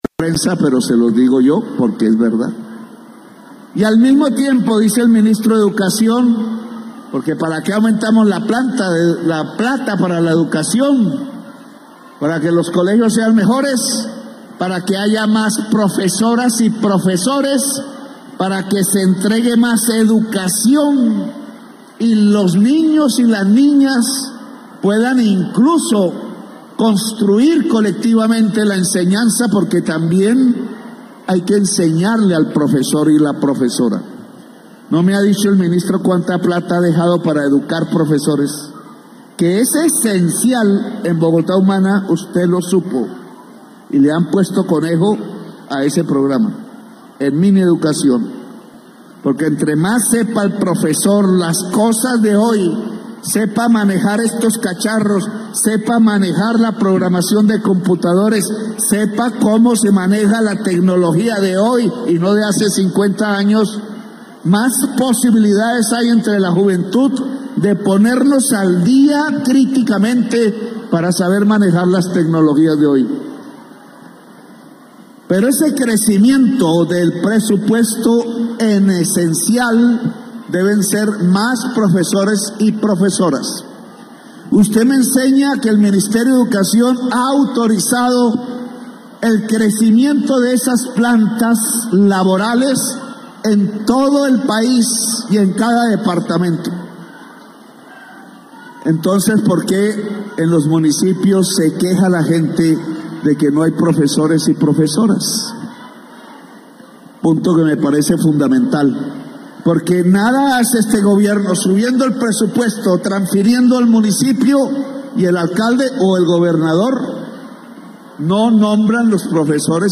Ocaña, Norte de Santander – Durante el lanzamiento de la jornada deportiva escolar complementaria, el presidente Gustavo Petro alzó su voz con una fuerte crítica hacia la falta de nombramientos docentes en las regiones, a pesar del aumento presupuestal destinado al sector educativo.
En un tono contundente, advirtió que si no se cumplen estas directrices, se expedirá un decreto presidencial y se solicitará intervención de la Procuraduría en los territorios donde se esté incumpliendo con los nombramientos.